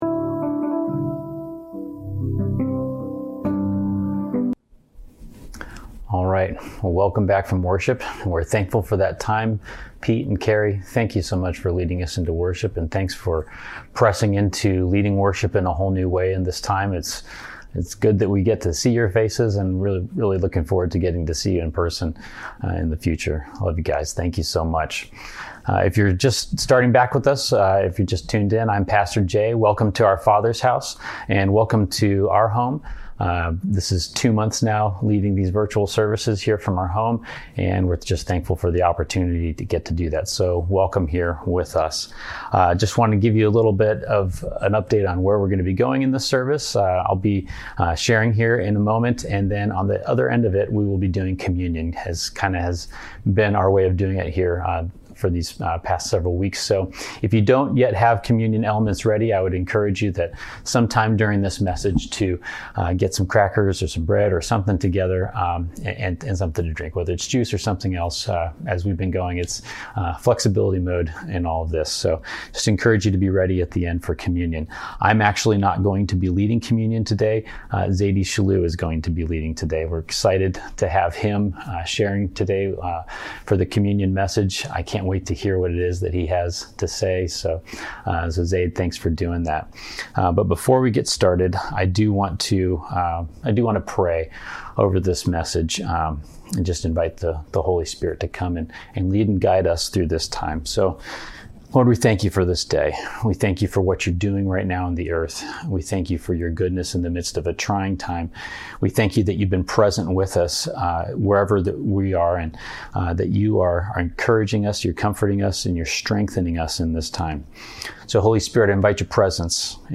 in an off-the-cuff message addressing the need for the church to lead in the healing and reconciliation between people groups.